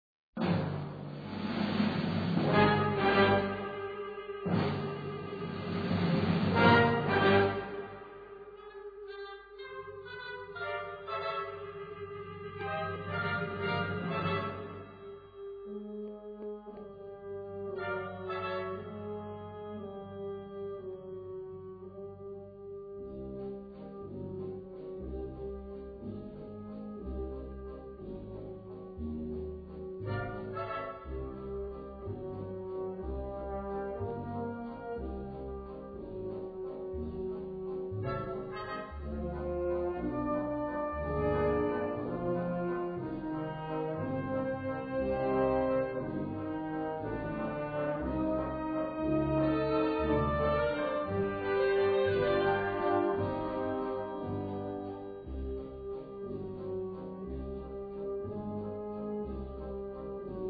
Gattung: Konzertwalzer
Besetzung: Blasorchester